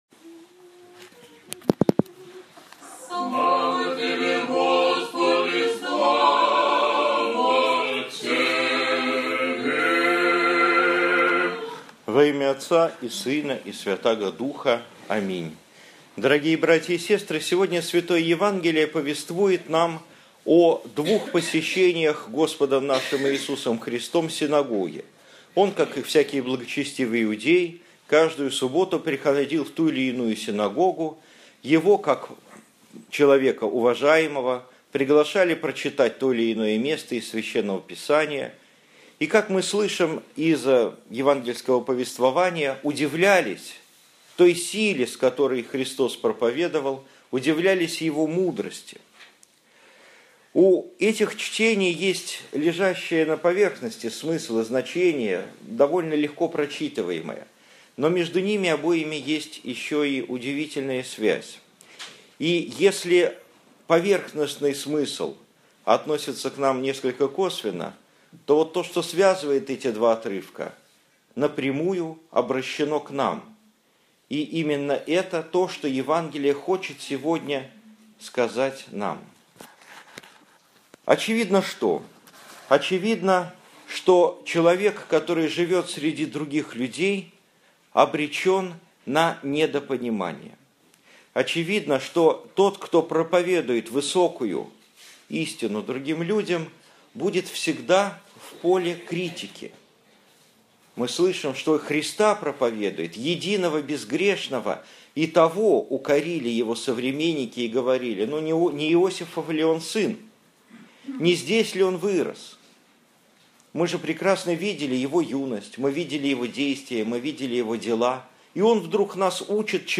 Домой / Проповеди / Аудио-проповеди / 10 октября 2015 года. Проповедь на литургии